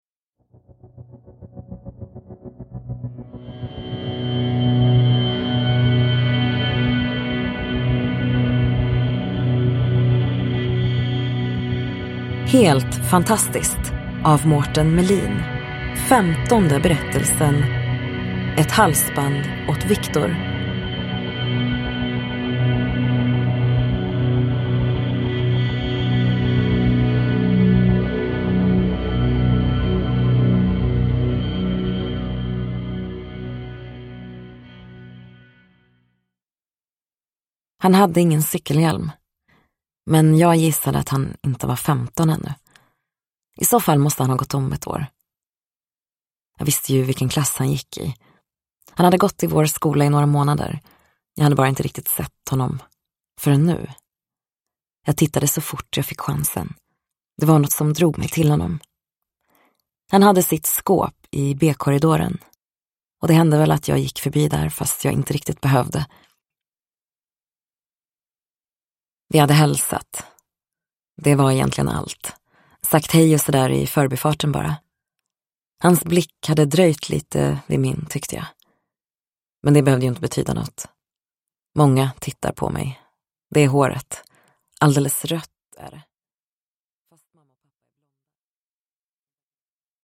Ett halsband åt Viktor : en novell ur samlingen Helt fantastiskt – Ljudbok – Laddas ner